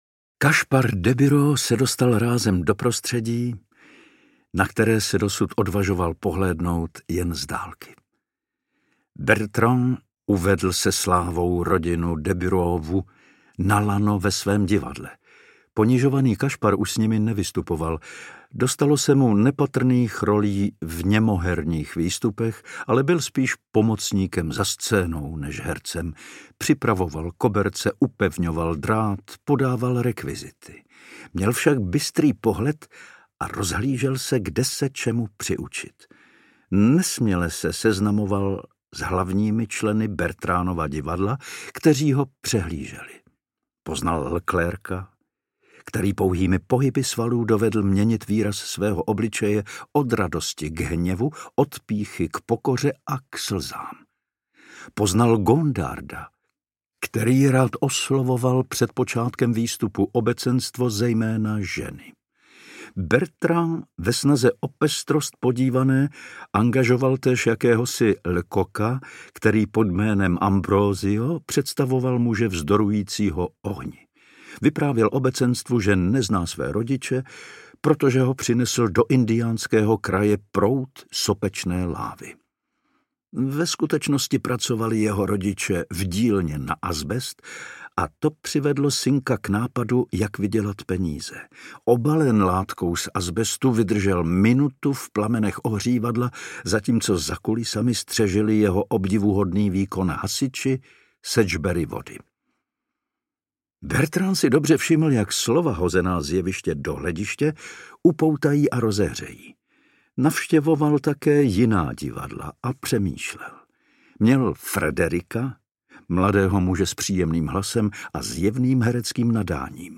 Největší z Pierotů audiokniha
Ukázka z knihy